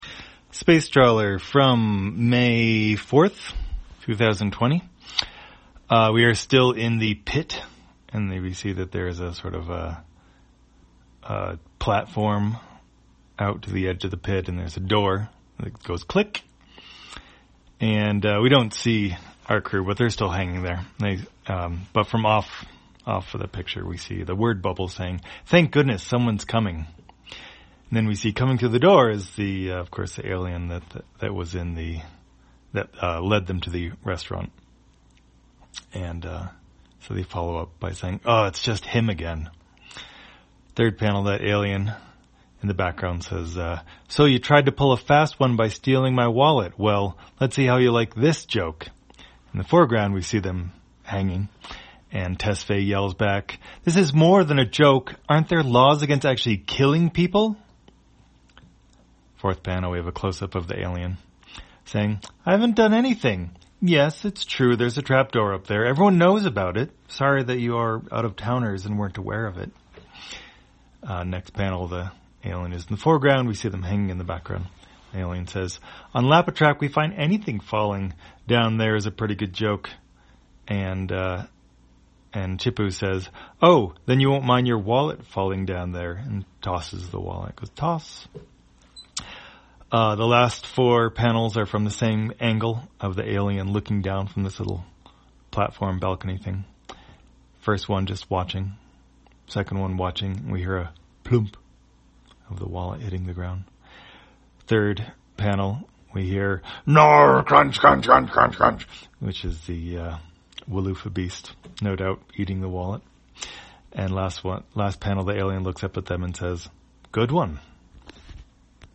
Spacetrawler, audio version For the blind or visually impaired, May 5, 2020.